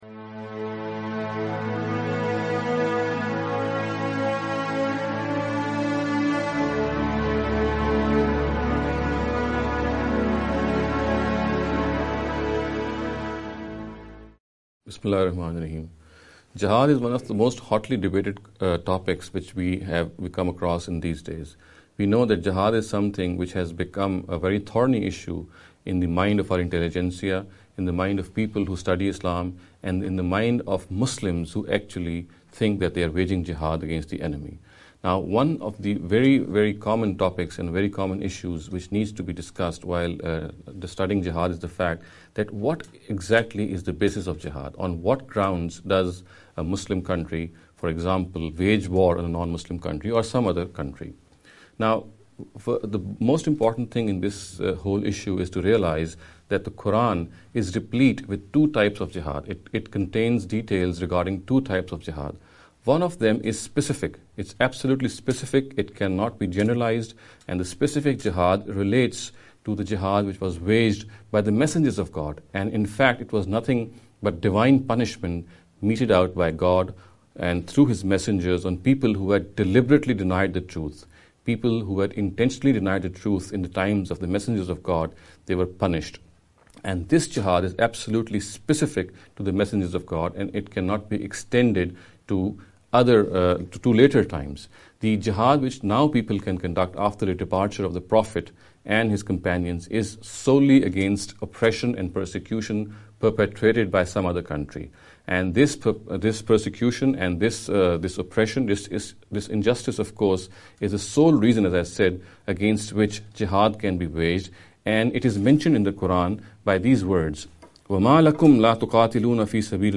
This lecture series will deal with some misconception regarding the Islam and Jihad.